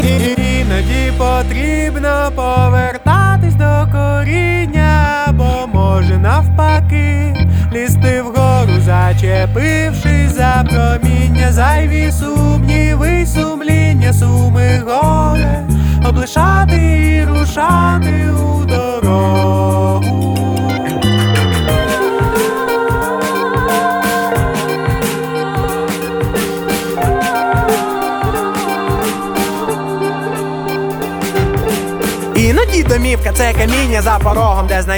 Жанр: Иностранный рок / Рок / Инди / Украинские
# Indie Rock